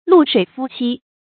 露水夫妻 注音： ㄌㄨˋ ㄕㄨㄟˇ ㄈㄨ ㄑㄧ 讀音讀法： 意思解釋： 指暫時結合的非正式夫妻；亦指不正當的男女關系。